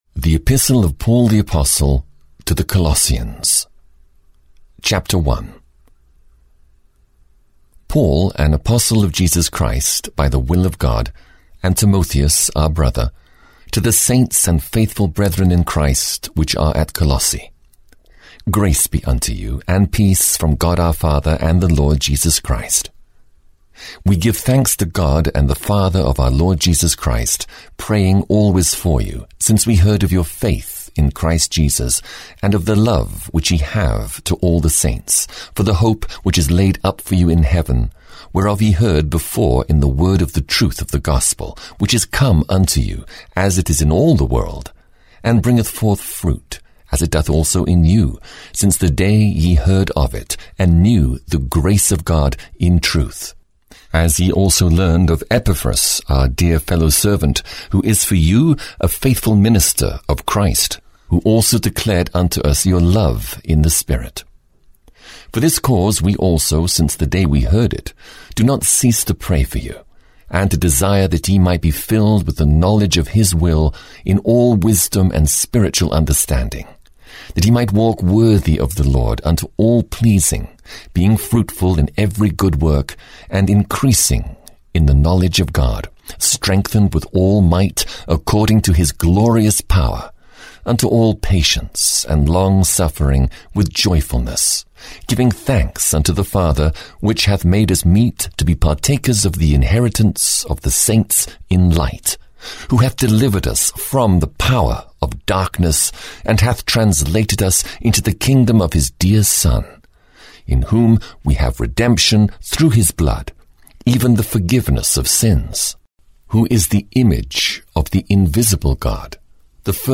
Audio knihaThe New Testament 12 - Colossians (EN)
Ukázka z knihy